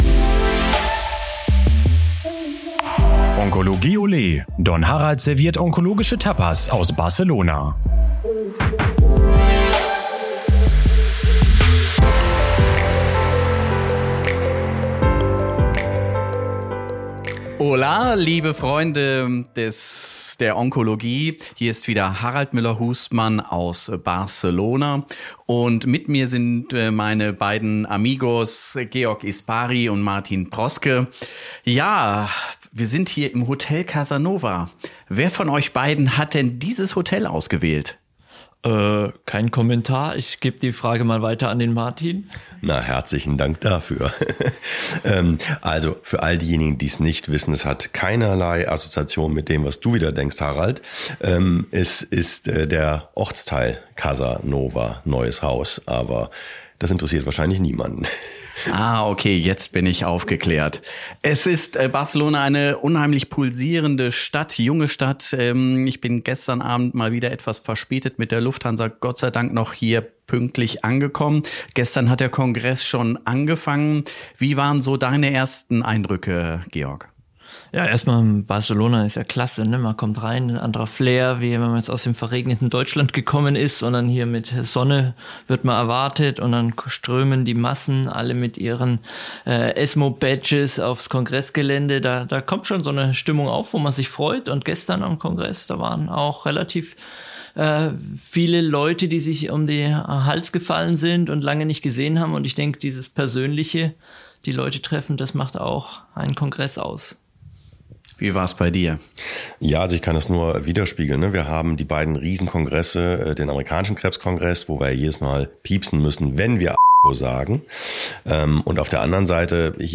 ExpertenDialoge - Krebstherapie im Wandel: Tapas der Onkologie vom ESMO 2024 aus Barcelona